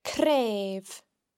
The cn sound is also heard in cnàimh (a bone):